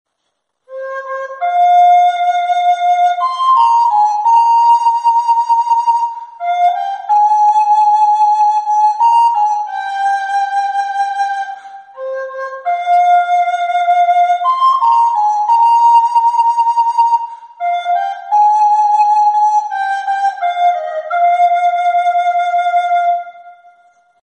Flauta Doce